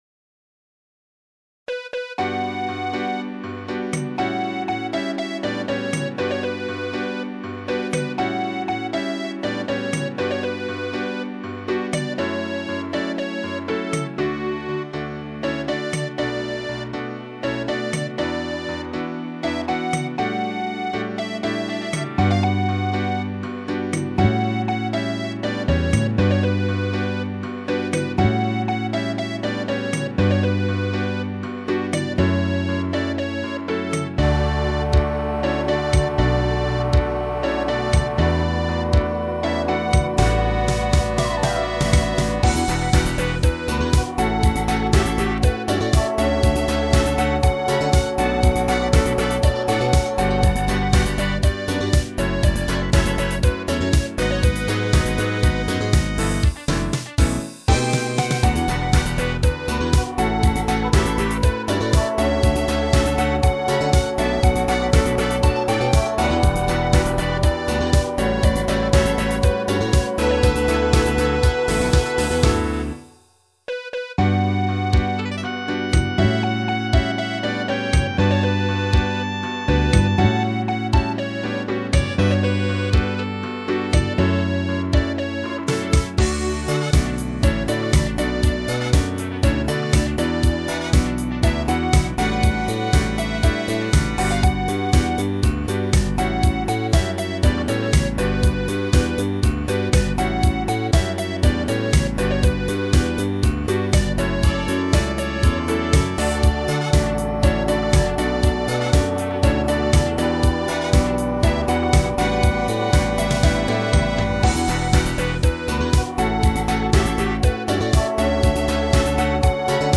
ブラスはテキトー。ギターもポジションなり。
エコー感を出すためにシンセストリングスを被せてみた。